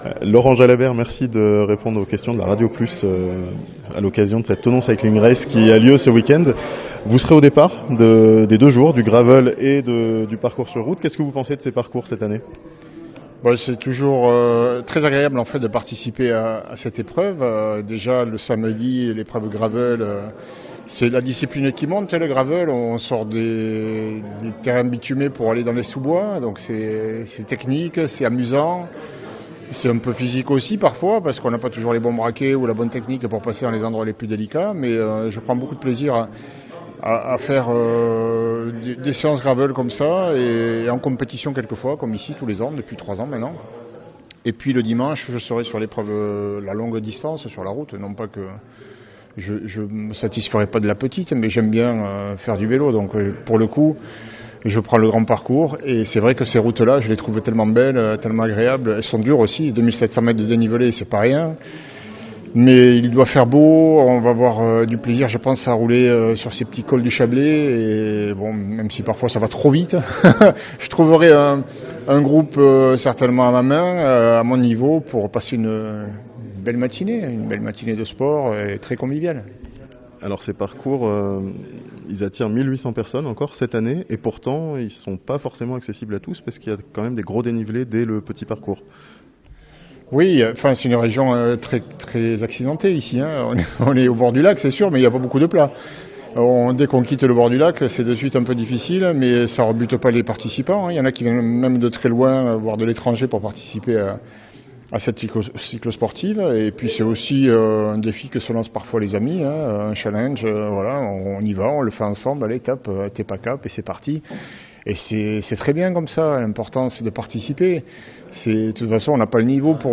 Laurent Jalabert au micro La Radio Plus